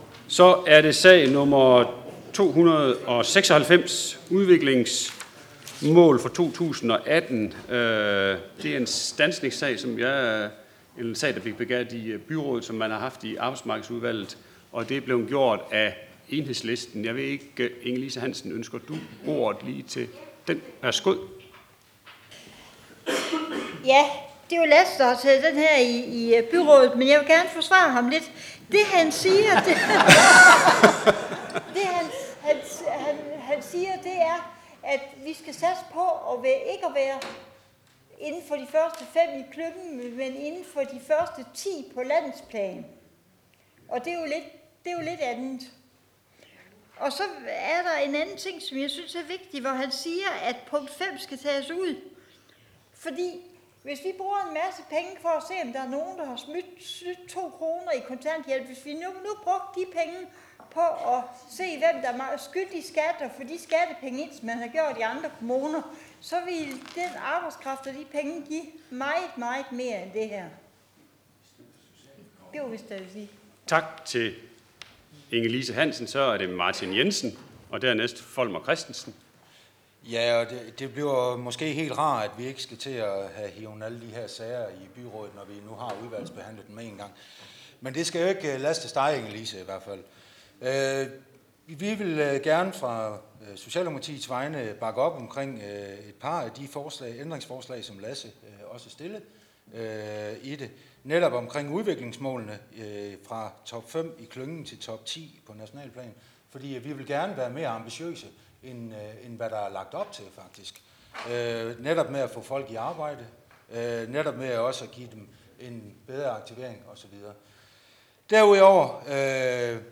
Hør lydklip fra byrådsmødet 13.12.17 - Vejle Kommune